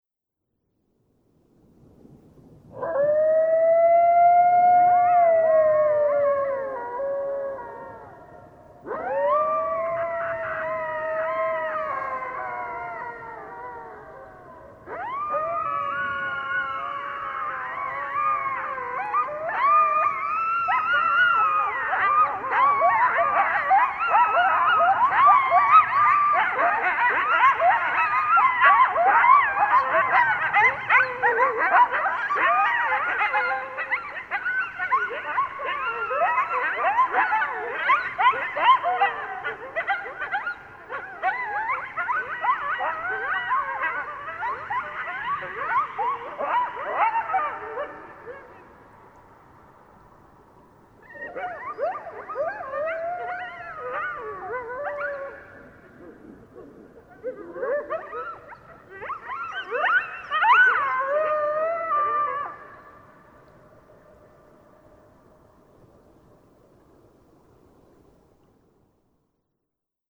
جلوه های صوتی
دانلود صدای کایوت برای کودکان از ساعد نیوز با لینک مستقیم و کیفیت بالا
برچسب: دانلود آهنگ های افکت صوتی انسان و موجودات زنده